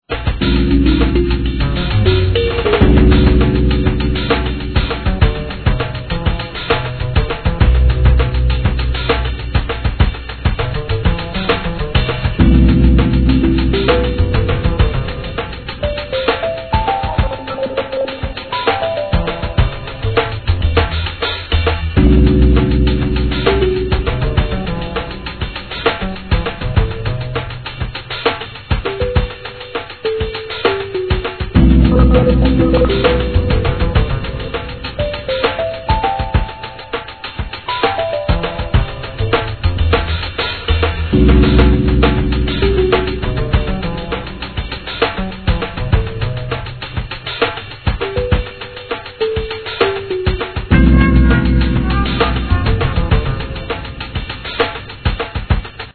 HIP HOPにエレクトロなAZZ,アブストラクトの融合したような好SOUND!!